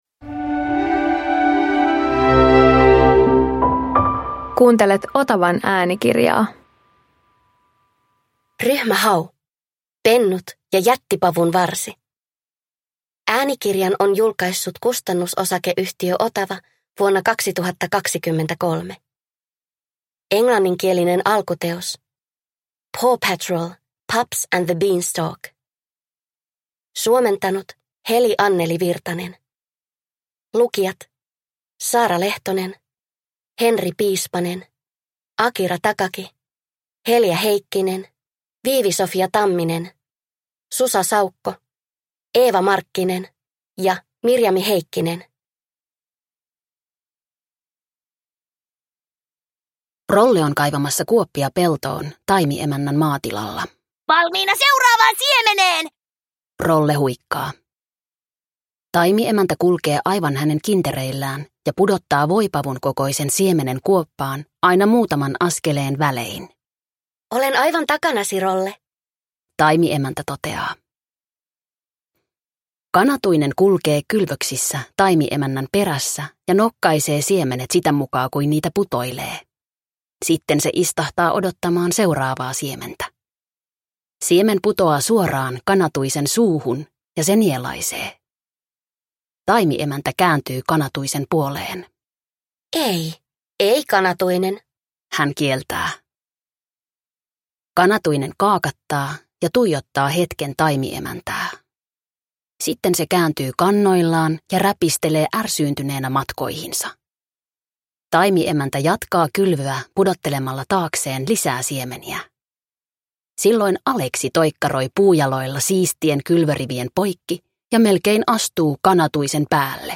Ryhmä Hau - Pennut ja jättipavunvarsi – Ljudbok